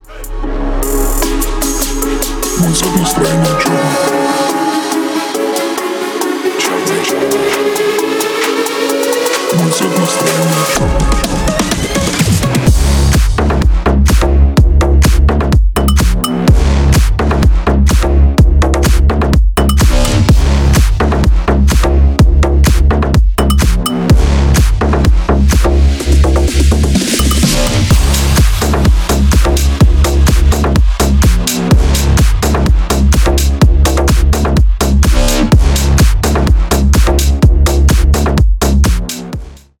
Ремикс # Танцевальные
клубные